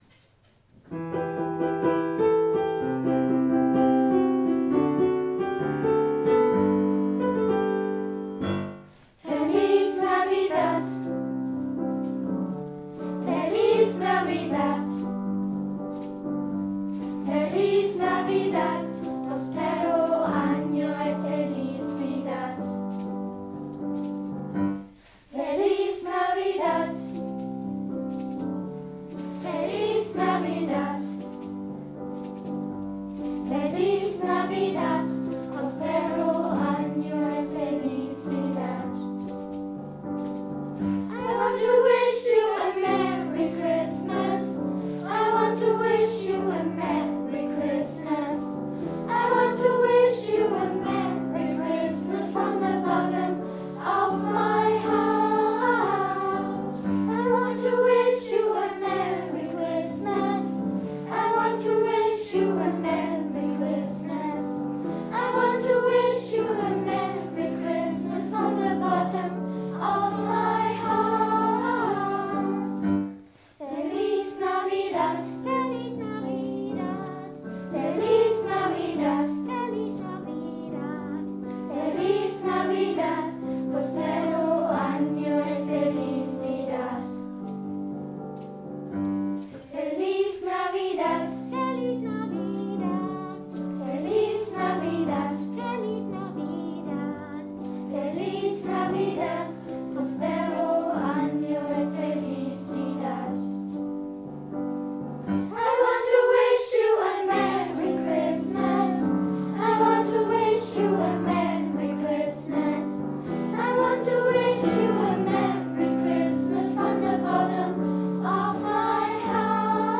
der Chor hat nach Schulschluss heimlich im Treppenhaus für Sie und euch ein paar Lieder gesungen, mit denen wir eine schöne Adventszeit und eine fröhliche Weihnachtszeit wünschen wollen!